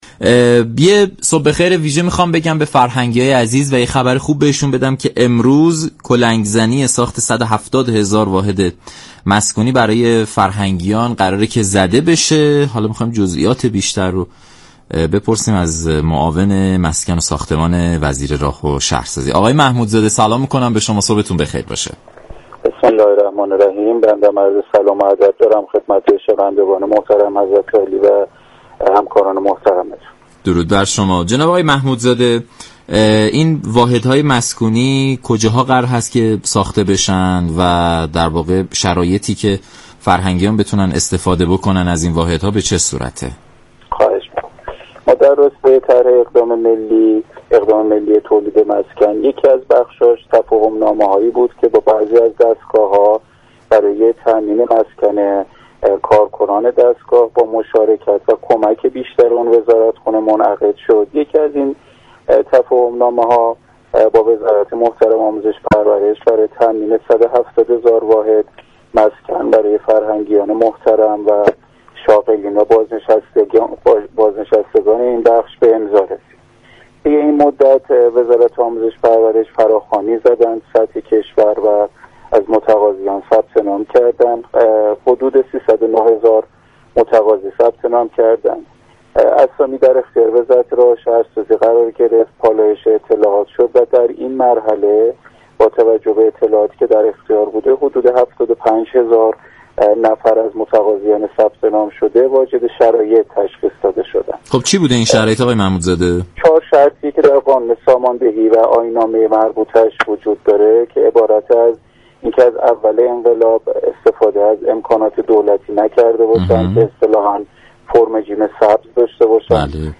معاون مسكن و ساختمان وزارت راه و شهرسازی در برنامه سلام صبح بخیر رادیو ایران گفت: در طرح احداث 170 هزار واحد مسكونی فرهنگیان، حدود 75 هزار نفر واجد شرایط شناخته شده اند.